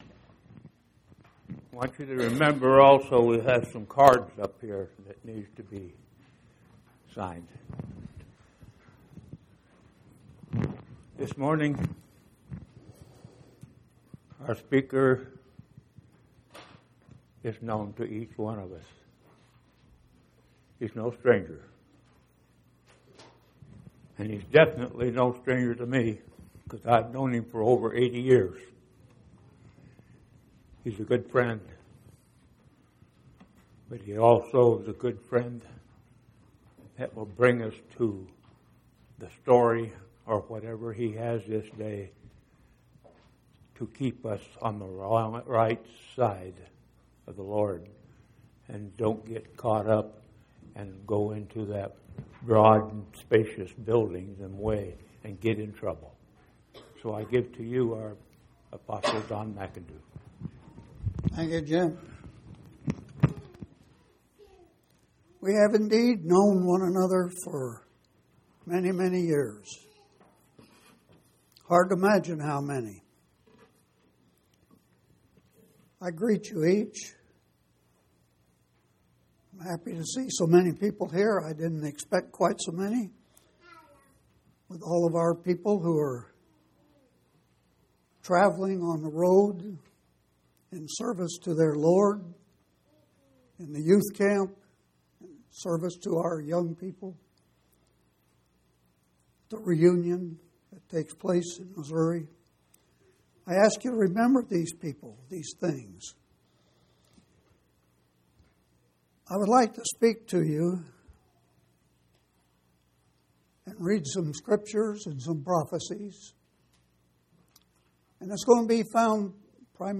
7/24/2016 Location: Phoenix Local Event